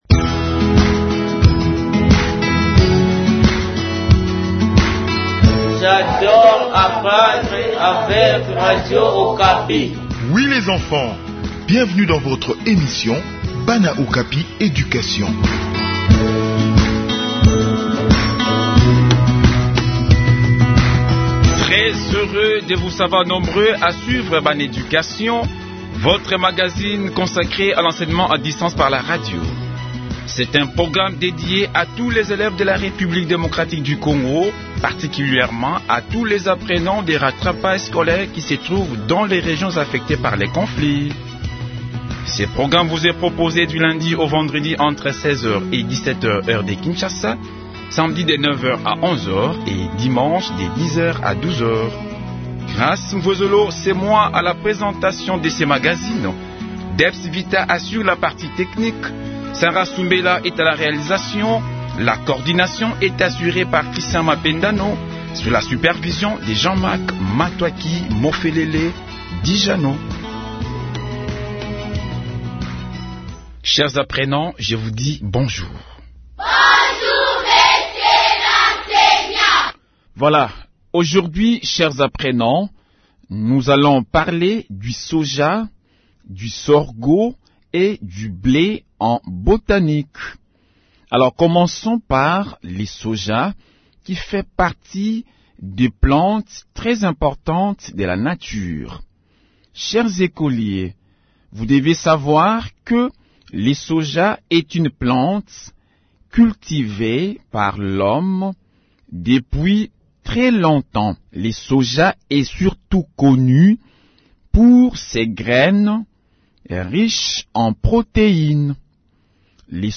Enseignement à distance : leçon sur le soja, le sorgho et le blé